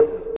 Woodblock (Goblin).wav